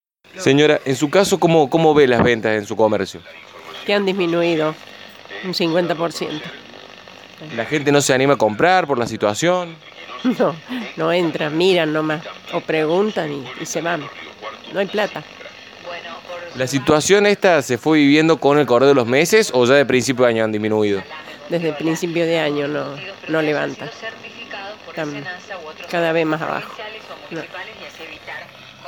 FM Eco dialogó con comerciantes de la ciudad, quienes expresaron su preocupación por la acuciante situación económica y la marcada caída en las ventas.
Aquí los testimonios obtenidos: